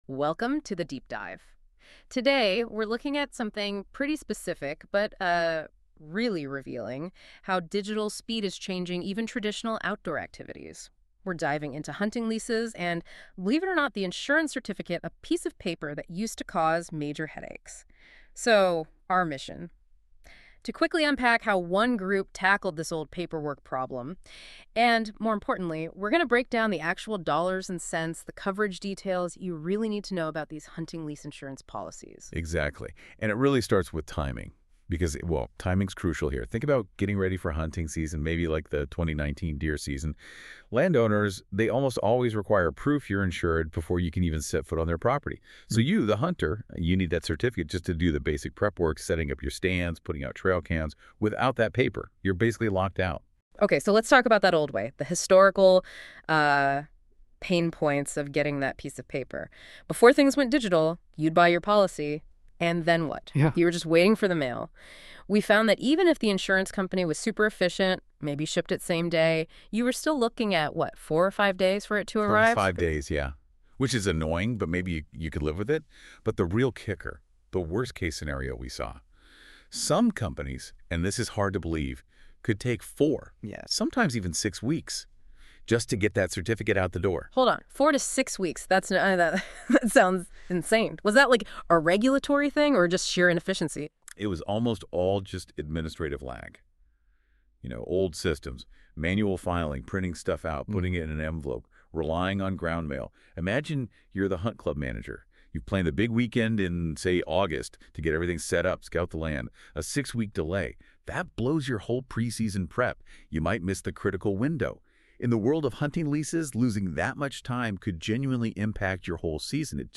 AI generated summary The provided source outlines the diverse insurance services and educational tools offered by the American Hunting Lease Association (AHLA). Specifically, the organization provides liability coverage tailored for hunting clubs, professional guides, and owners of vacant land.